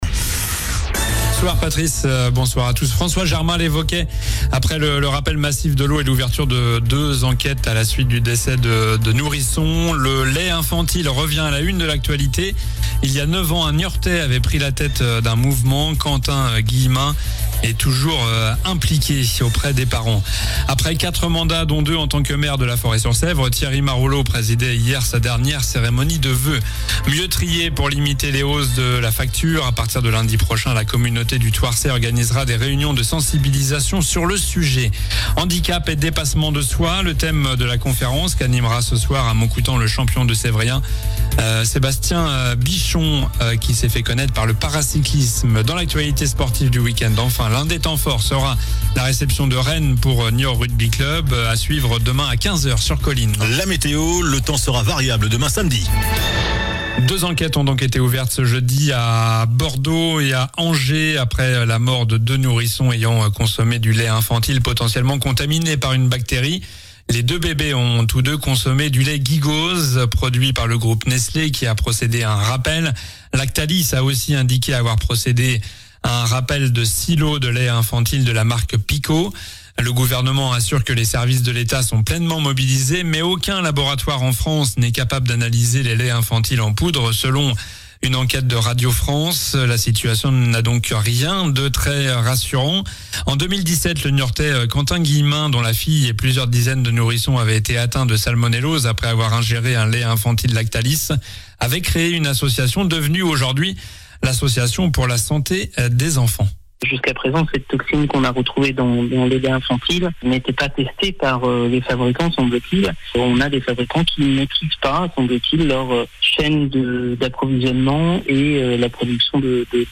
Journal du vendredi 23 janvier (soir)